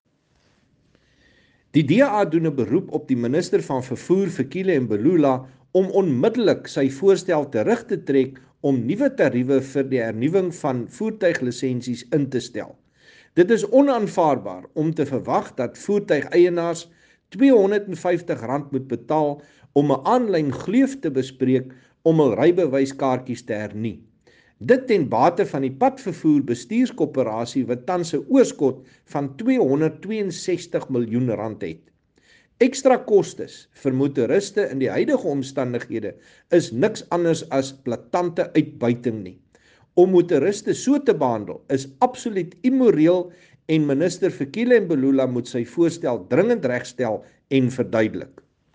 Afrikaans soundbites by Chris Hunsinger MP.